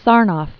(särnôf), David 1891-1971.